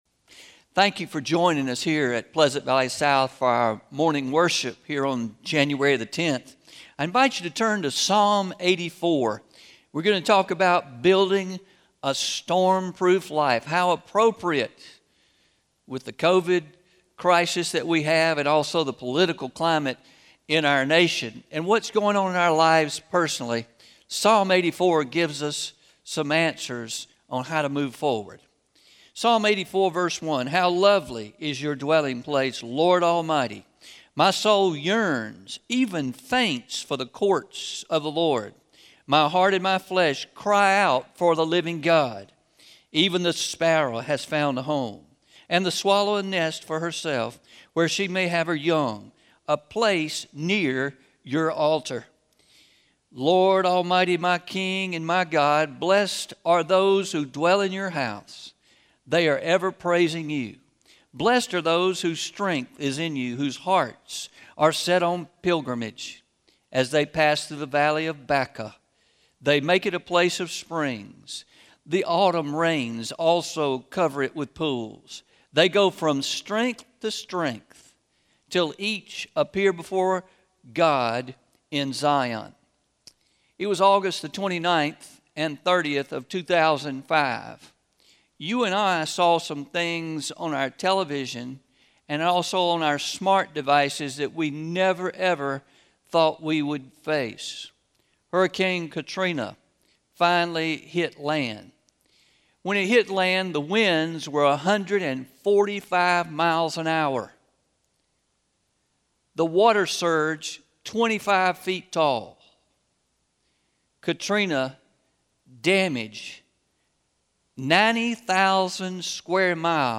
01-10-21am Sermon – Building a Storm Proof Life – Traditional